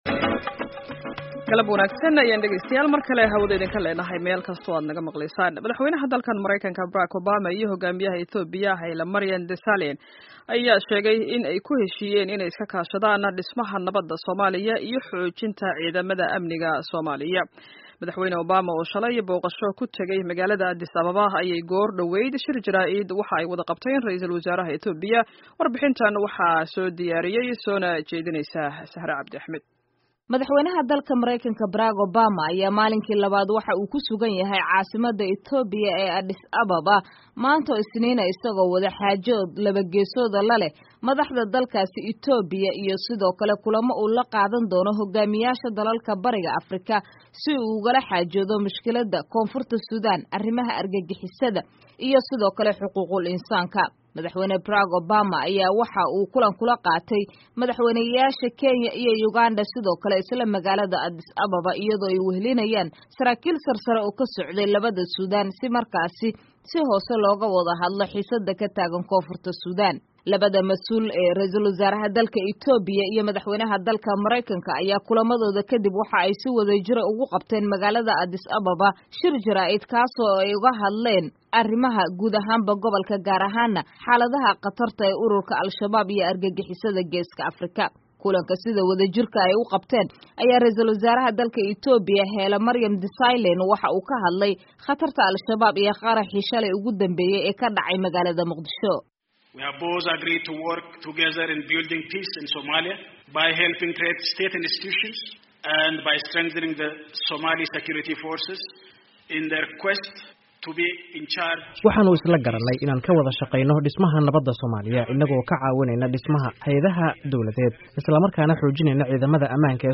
Dhageyso Warbixin Boqashada Obama ee Ethiopia